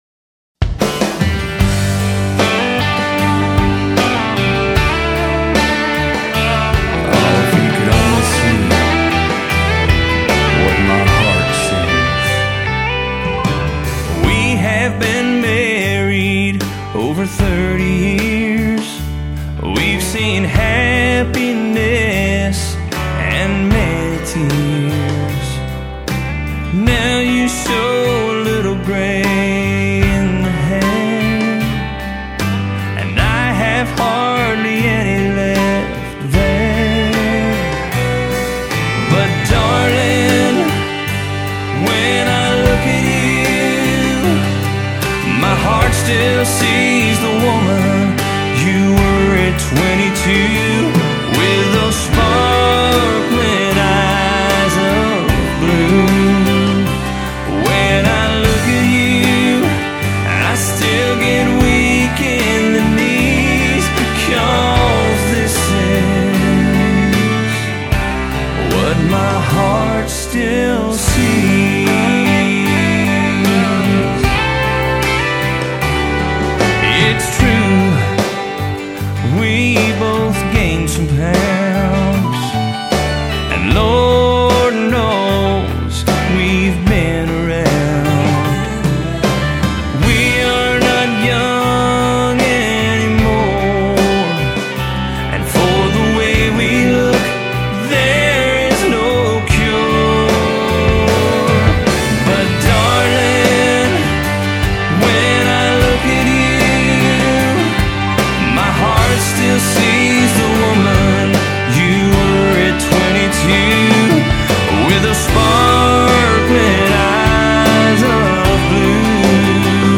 (country)